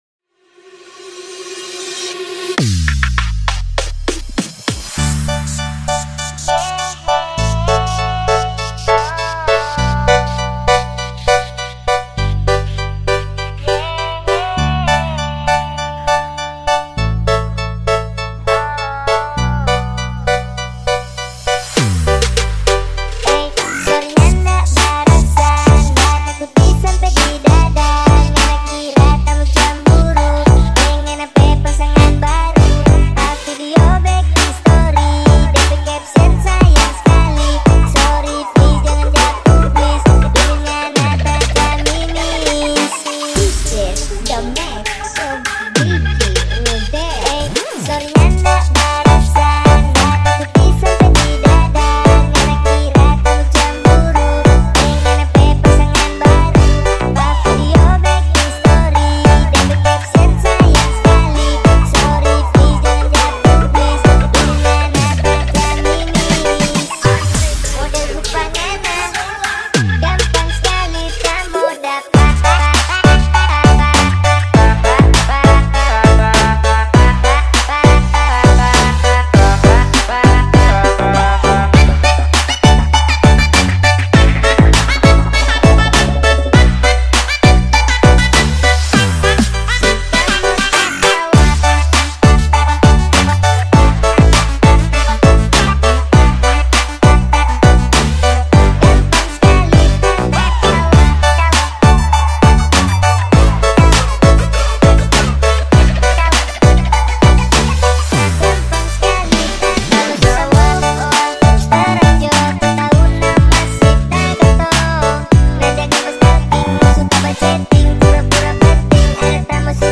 SLOWED ( FULL BASS REMIX )